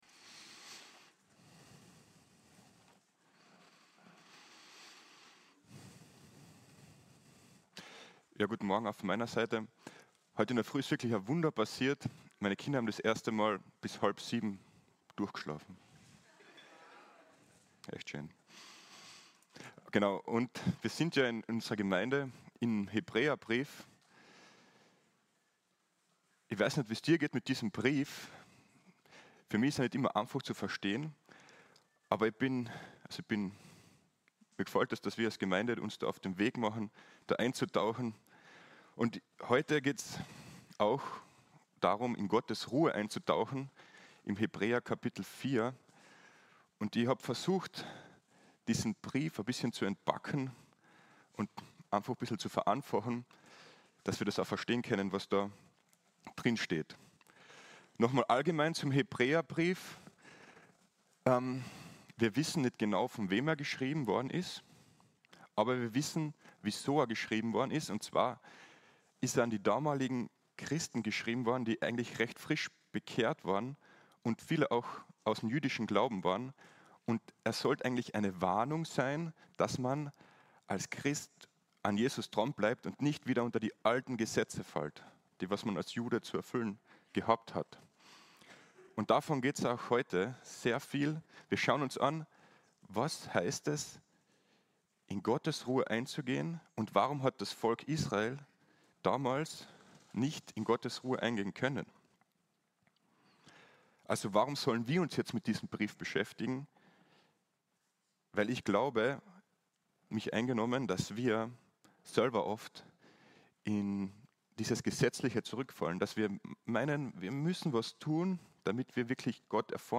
Aktuelle Predigtreihe – FEG Klagenfurt